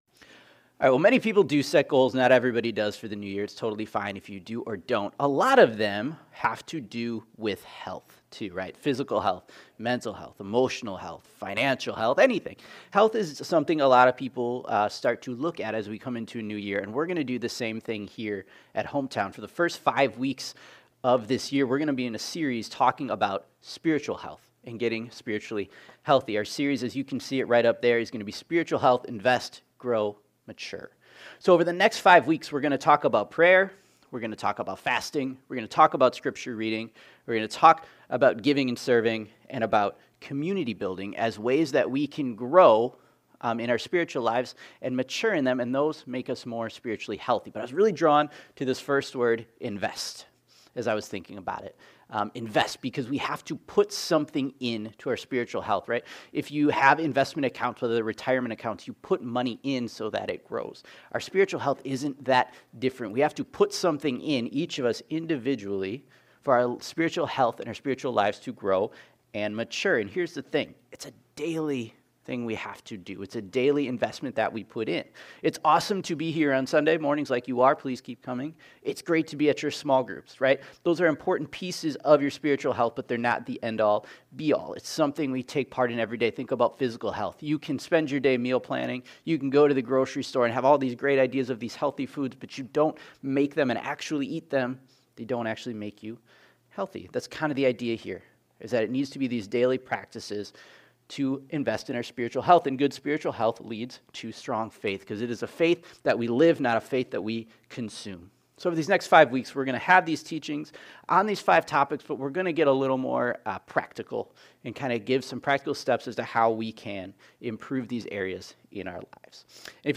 2026 Spiritual Health Ask Praise Prayer Repentance Yield Sunday Morning To start a new year many people focus on different ways of being healthy.